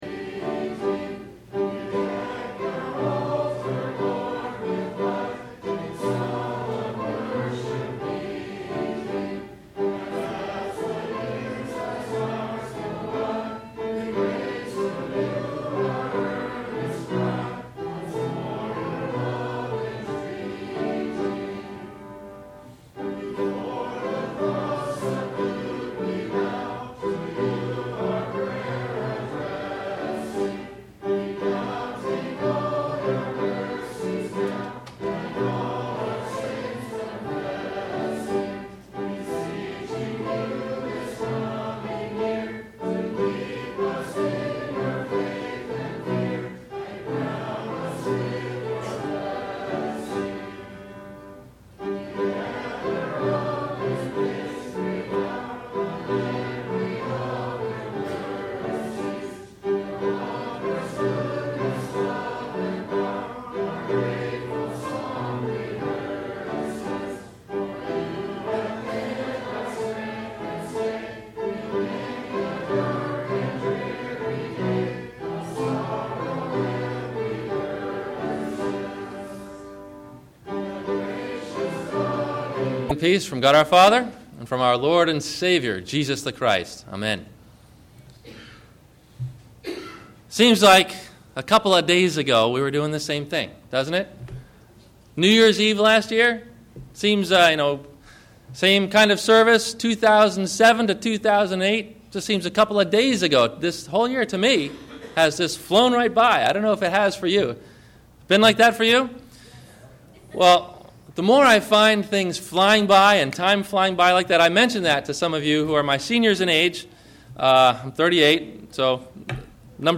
New Years Eve – Sons of the Day - Sermon - December 31 2008 - Christ Lutheran Cape Canaveral